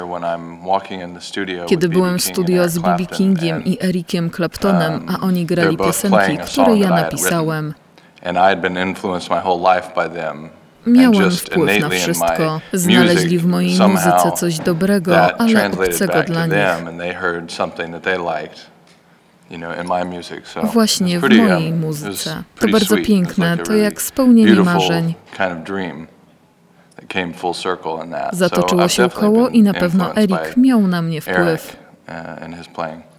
konfa-dzwiek-z-nalozonym-tlumaczeniem.mp3